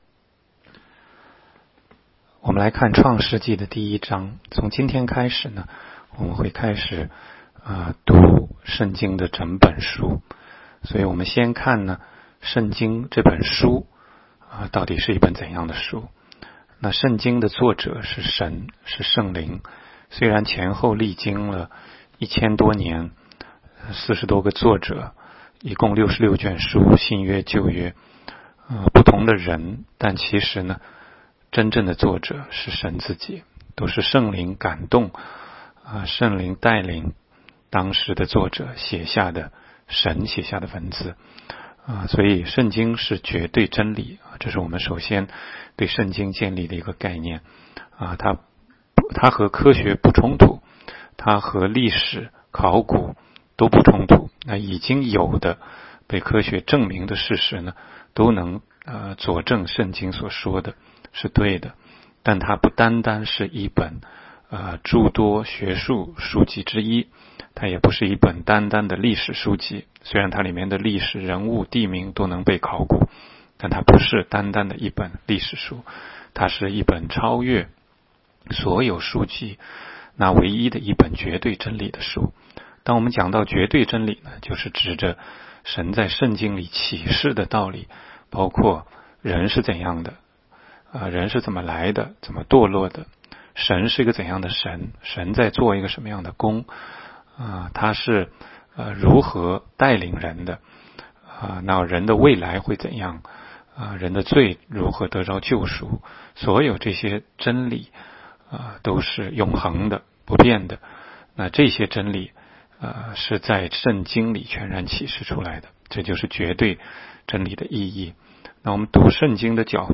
16街讲道录音 - 每日读经-《创世记》1章